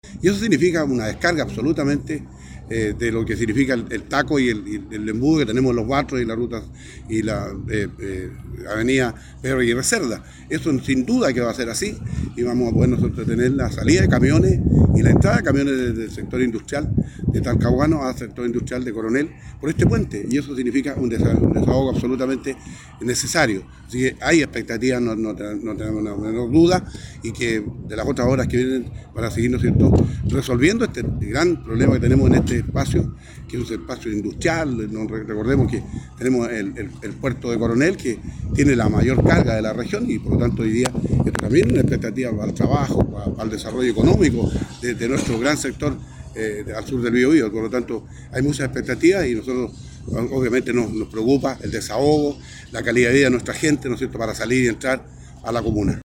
Por su parte, el alcalde de San Pedro de la Paz, Javier Guiñez, valoró el avance de las obras, sobre todo porque “significa una descarga del embudo que tenemos en Los Batros y la avenida Pedro Aguirre Cerda y vamos a tener una entrada y salida de camiones desde el sector industrial por este puente”.